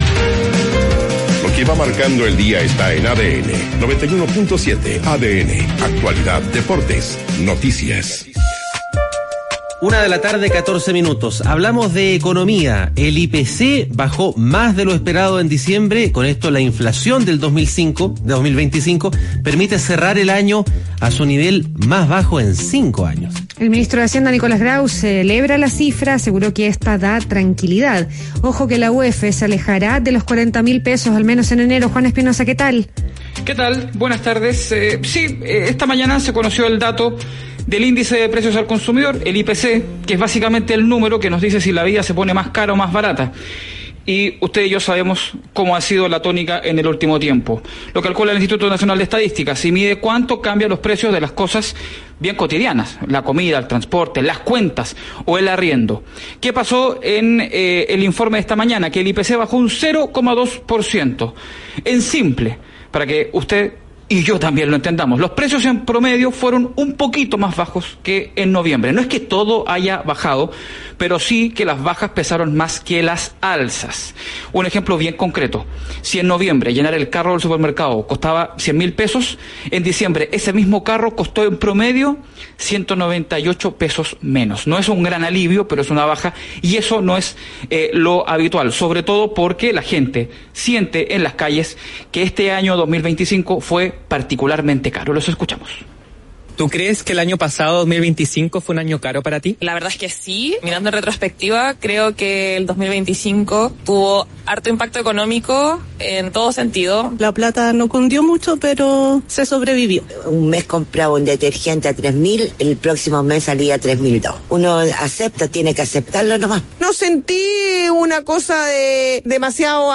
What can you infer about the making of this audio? Jan 8, 2026: Brief interview in Radio ADN about the last inflation statistics in Chile.